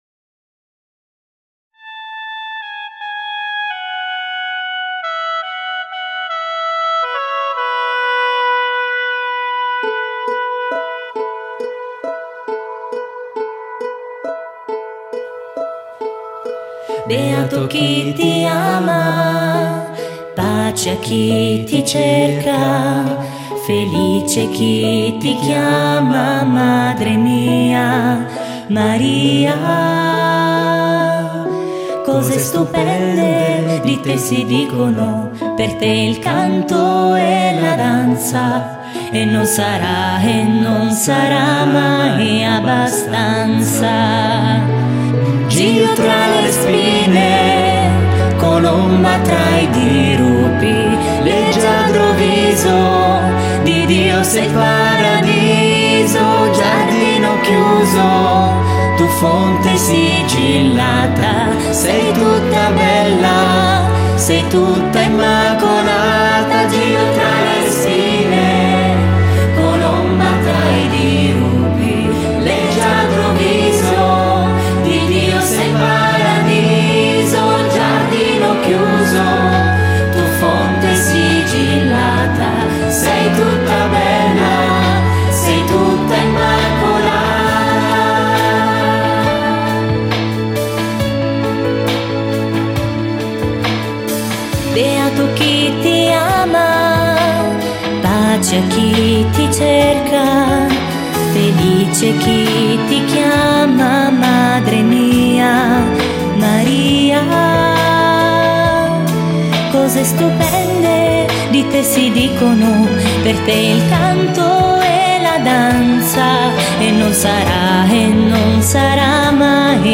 Canto per la Decina di Rosario e Parola di Dio: Beato chi ti ama